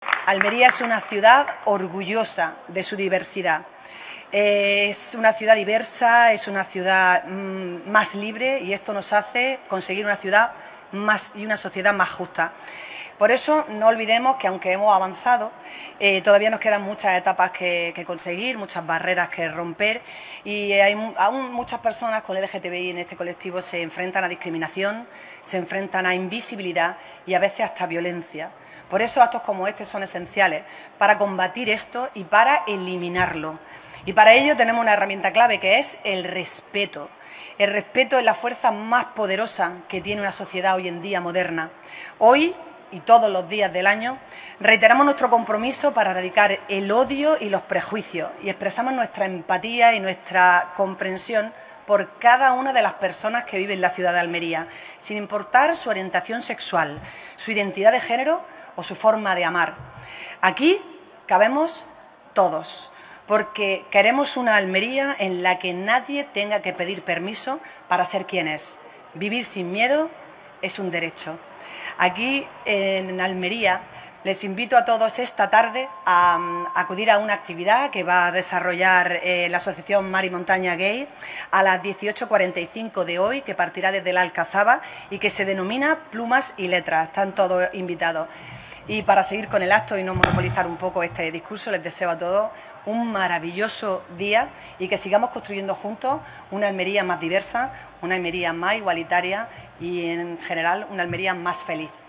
La alcaldesa destaca en el acto institucional celebrado este viernes en la Plaza Pablo Cazard “la importancia de reafirmar el compromiso con la igualdad, la diversidad y el respeto”